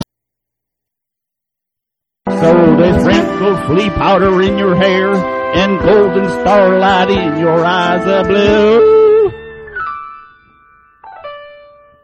Rock and Roll classics